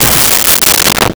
Bass Drum Cymbal Hit 09
Bass Drum Cymbal Hit 09.wav